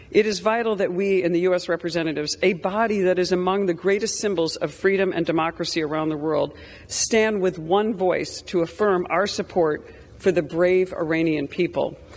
Congresswoman Tenney spoke on the floor of Congress.